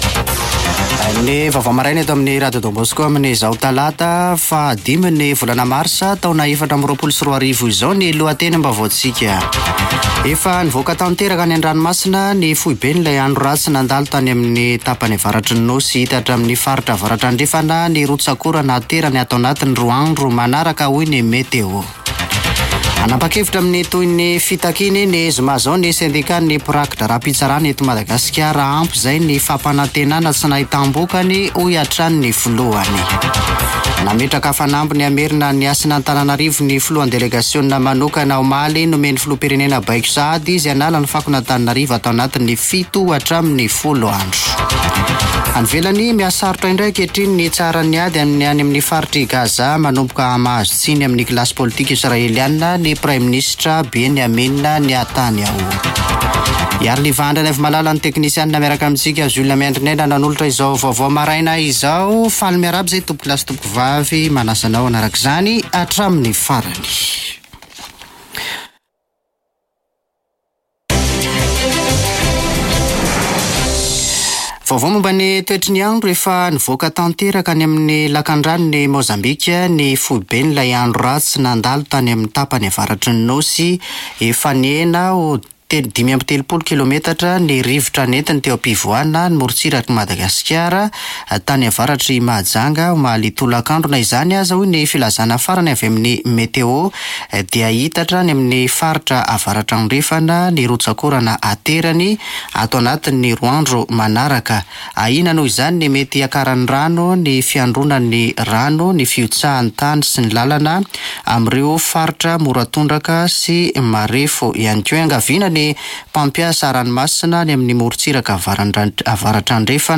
[Vaovao maraina] Talata 5 marsa 2024